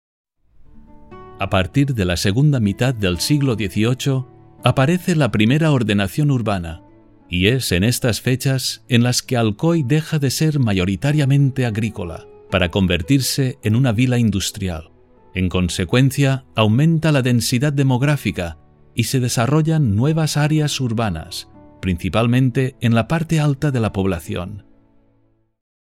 A Neumann U87 mic is used for all voice work.
Spanish (castilian) voice with over 20 years experience, can be a warm voice or authoratative.
kastilisch
Sprechprobe: Sonstiges (Muttersprache):